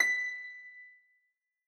Harpsicord
b6.mp3